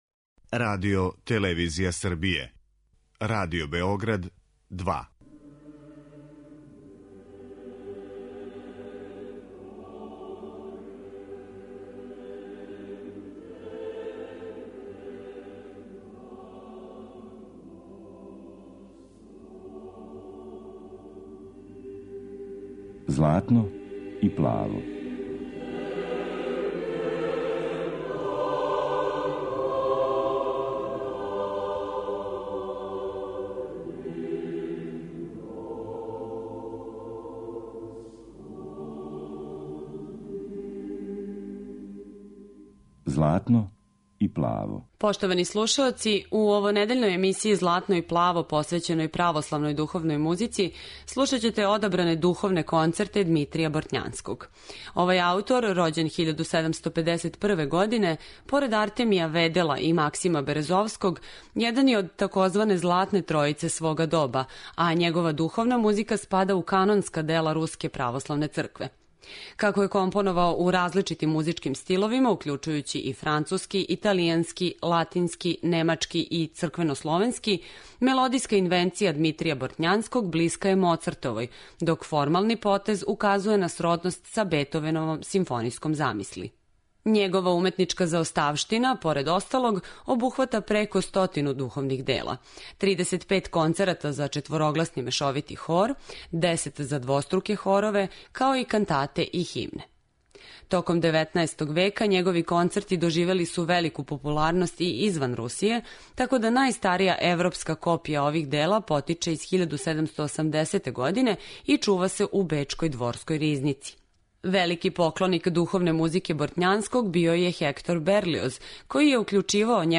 У овонедељној емисији православне духовне музике, слушаћете одабране духовне концерте Дмитрија Бортњанског.